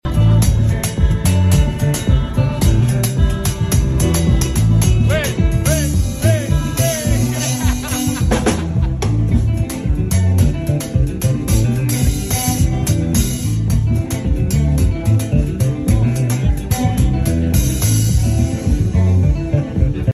Pandan map fè sound check sound effects free download